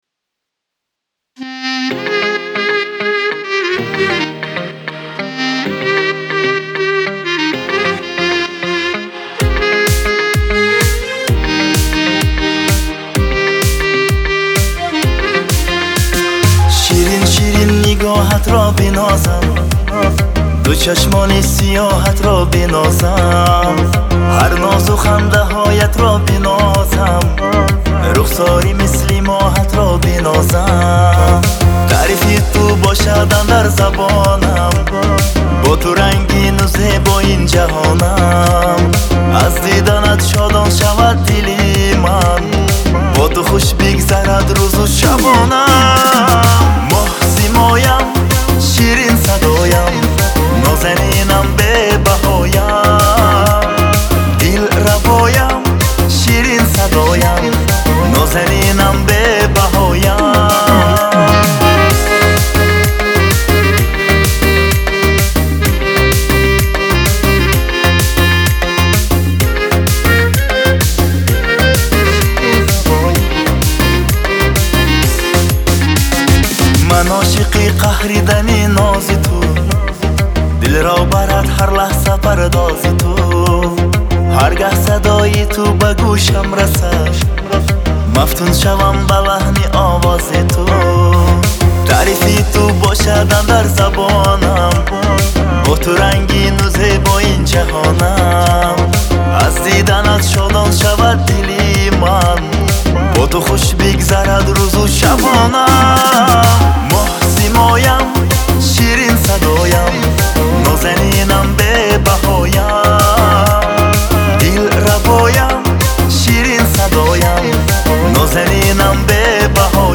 Грустные
Трек размещён в разделе Узбекская музыка / Поп.